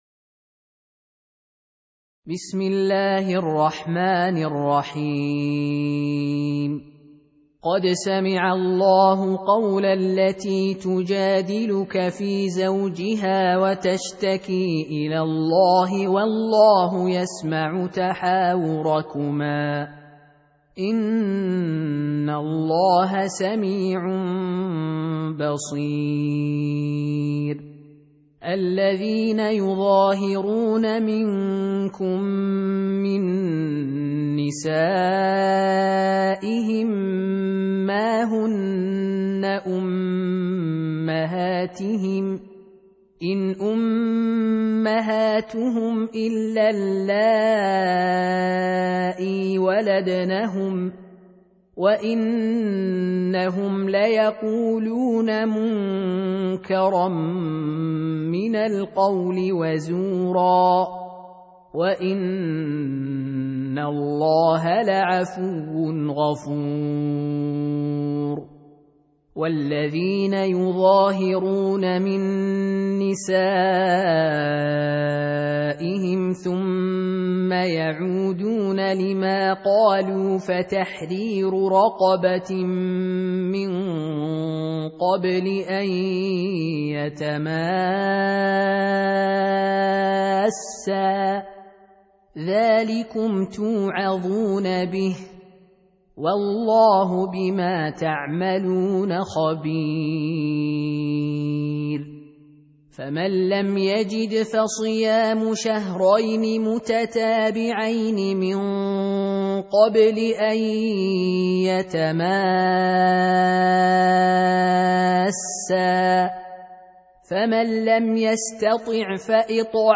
Surah Repeating تكرار السورة Download Surah حمّل السورة Reciting Murattalah Audio for 58. Surah Al-Muj�dilah سورة المجادلة N.B *Surah Includes Al-Basmalah Reciters Sequents تتابع التلاوات Reciters Repeats تكرار التلاوات